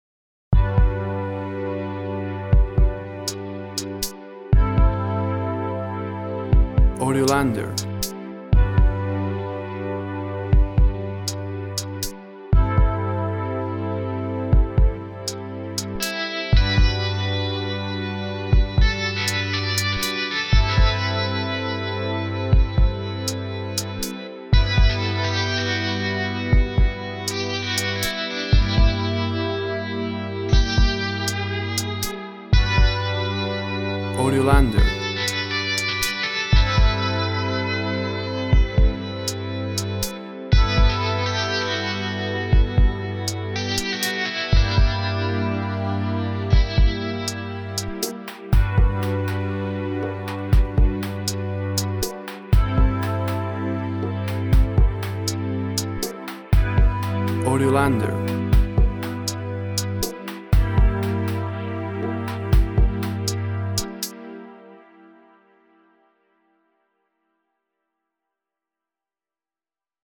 Contemplative guitar, with strings  pad.
WAV Sample Rate 16-Bit Stereo, 44.1 kHz
Tempo (BPM) 120